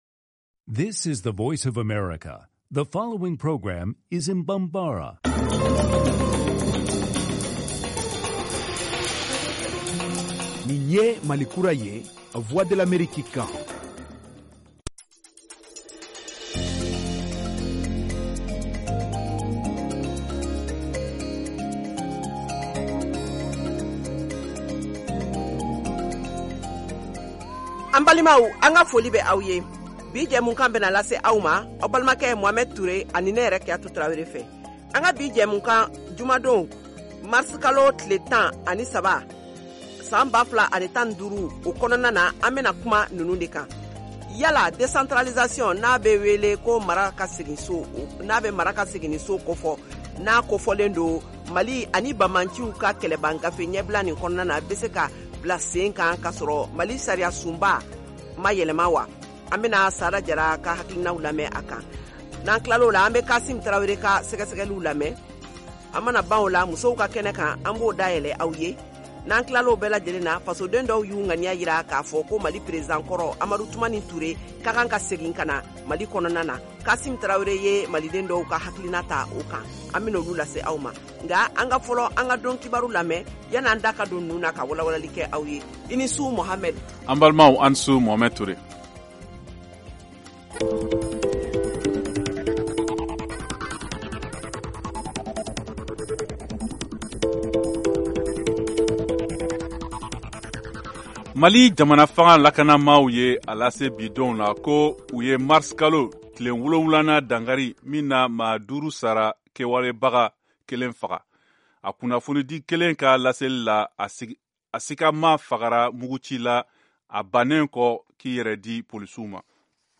Emission quotidienne
en direct de Washington, DC, aux USA